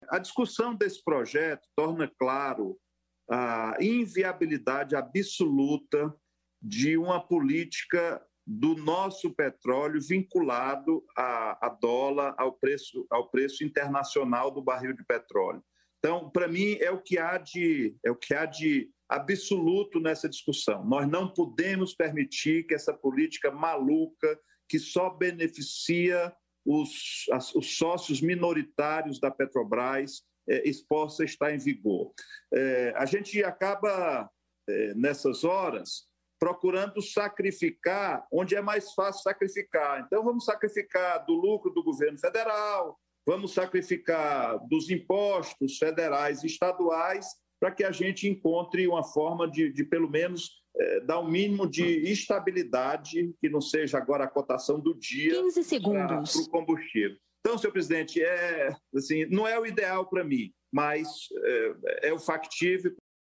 Cid Gomes critica política da Petrobras - Senador criticou política de preços dos combustíveis em vigor no país durante a sessão do Senado.